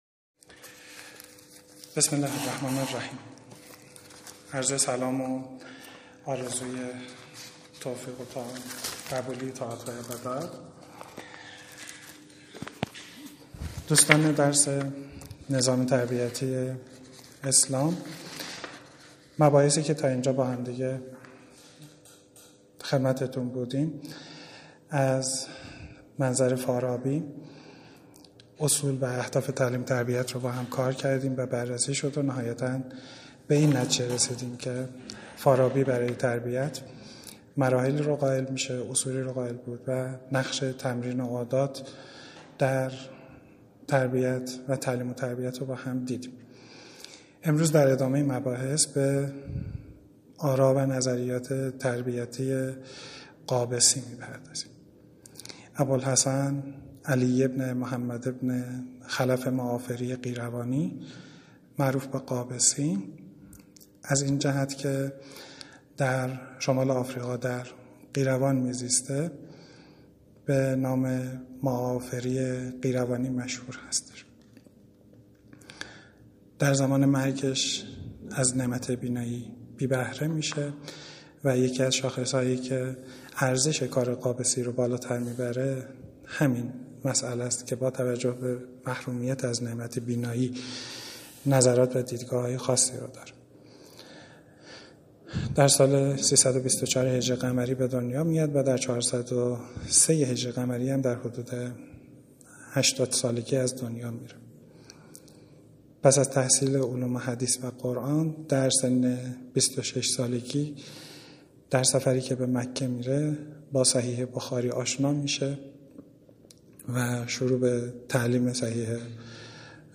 نظام تربیتی اسلام کلاس